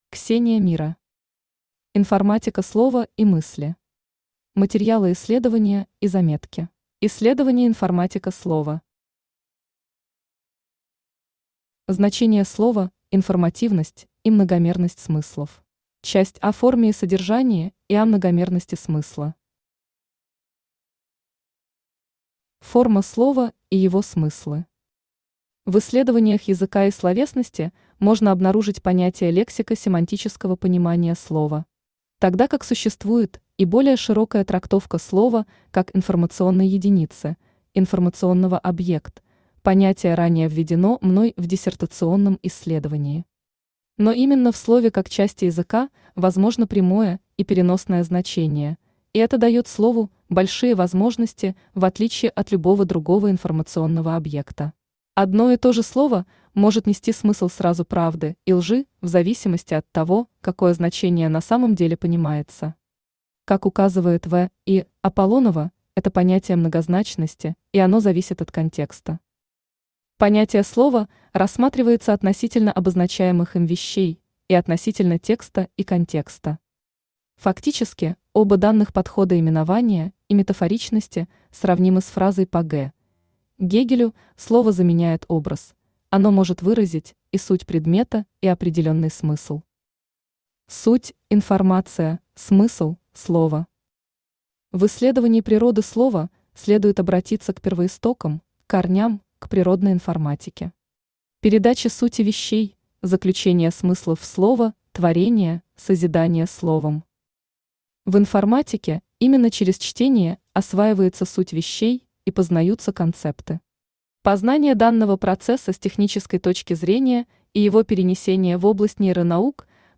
Аудиокнига Информатика слова и мысли. Материалы исследования и заметки | Библиотека аудиокниг
Aудиокнига Информатика слова и мысли. Материалы исследования и заметки Автор Ксения Мира Читает аудиокнигу Авточтец ЛитРес.